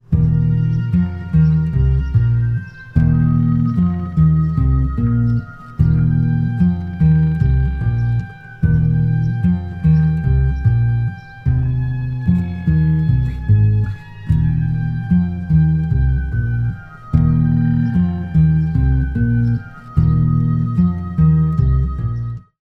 74 bpm
12-string LucyTuned guitar